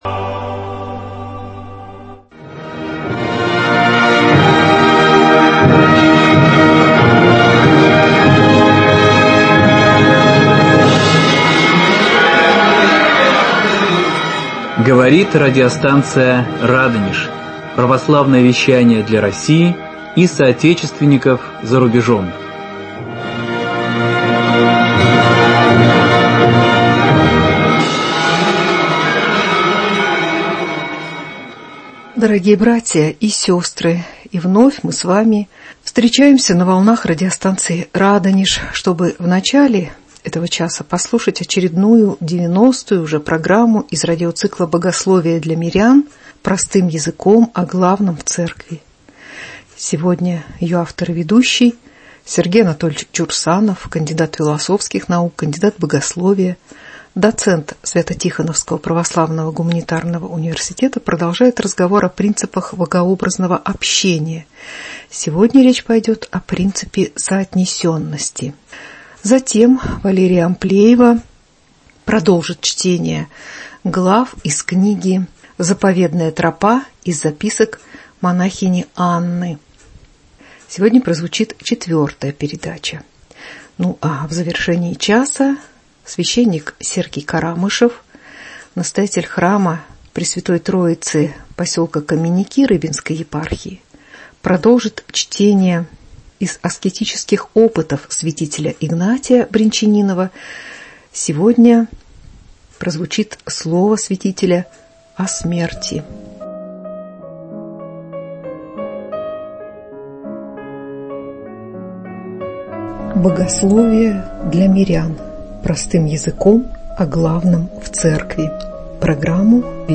продолжает чтение книги